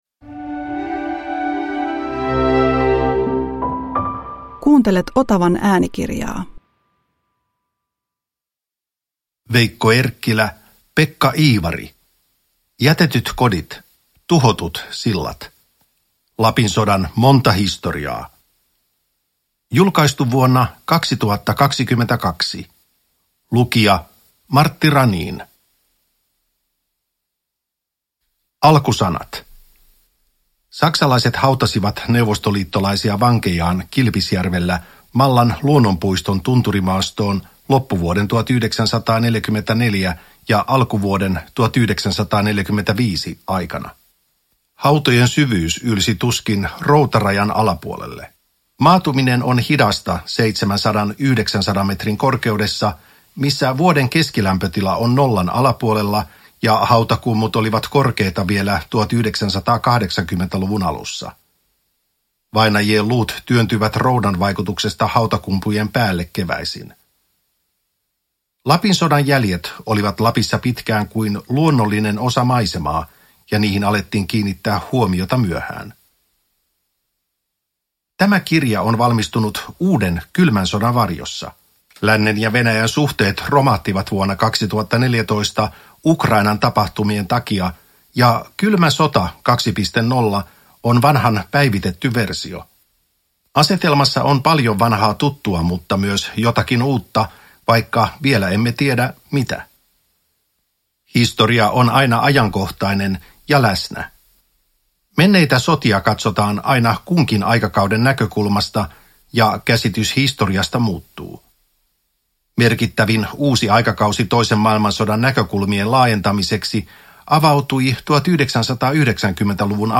Jätetyt kodit, tuhotut sillat – Ljudbok – Laddas ner